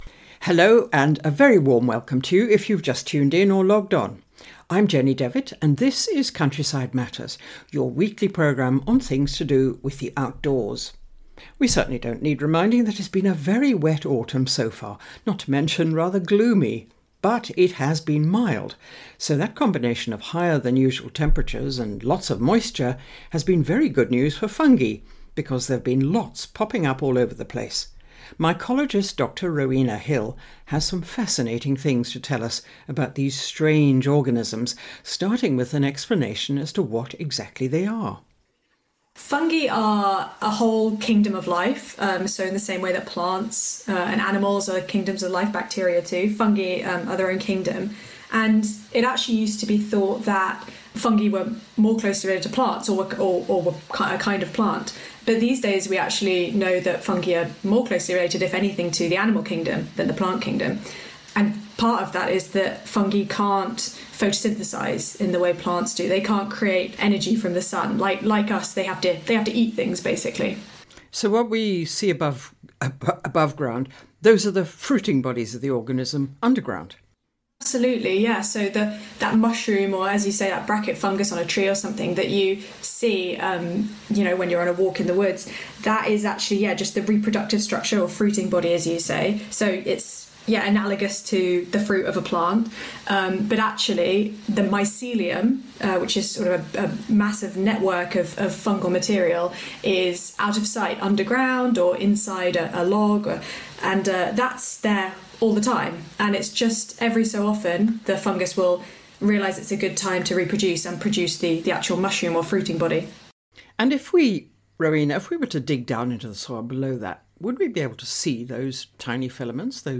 Countryside_Matters_interview_Nov_2024.mp3